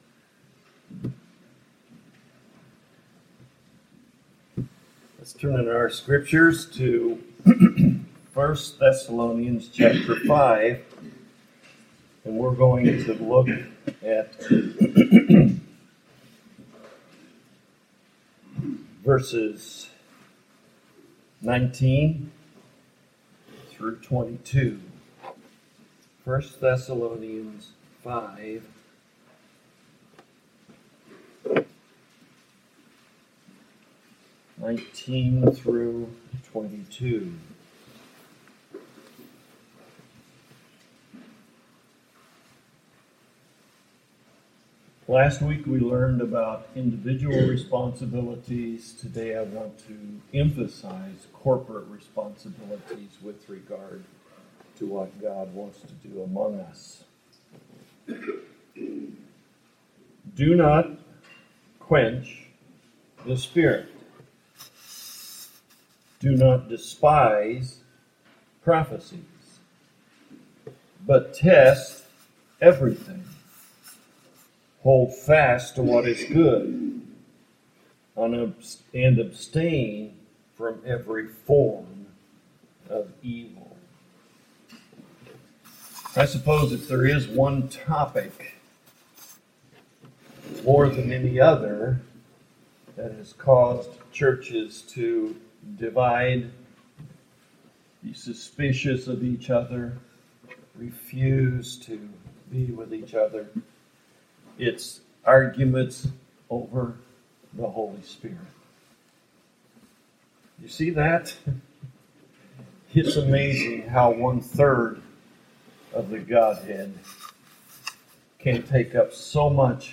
1 Thessalonians Passage: 1 Thessalonians 5:19-22 Service Type: Morning Worship Topics